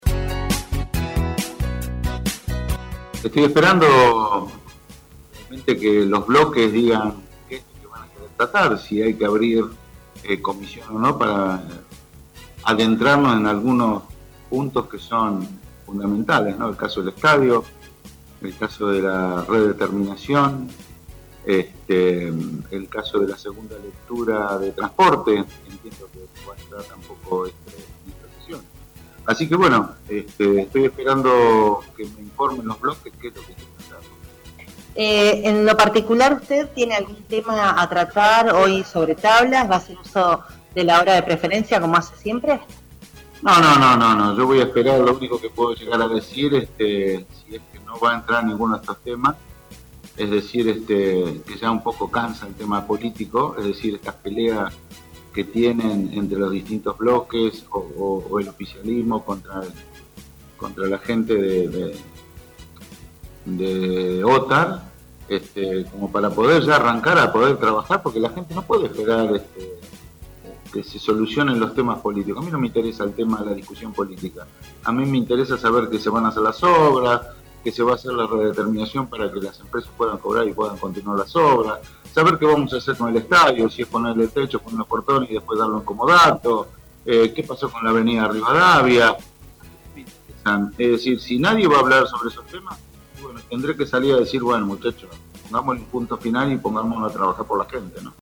En la previa a la sesión ordinaria del Concejo Deliberante, el concejal Omar Lattanzio habló con RADIOVISIÓN adelantando de su actitud de cara a esta sesión: